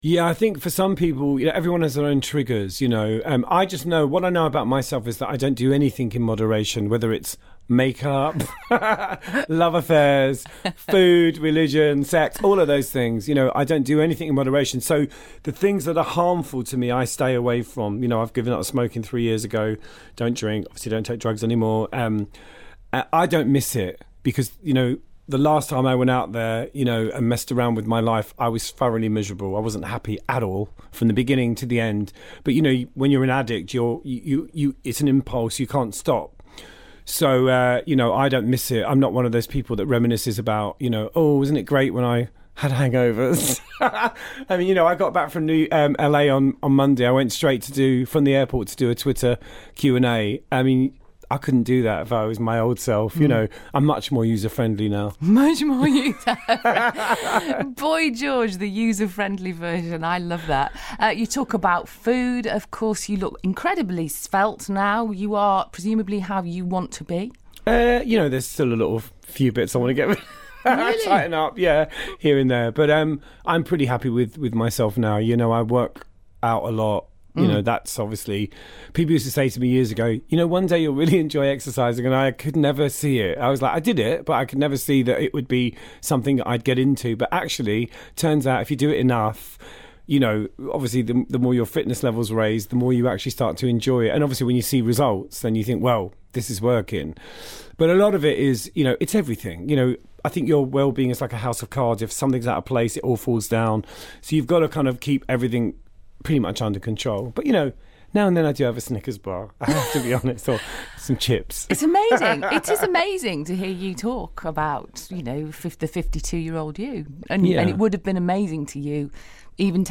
Boy George speaking to BBC Radio Manchester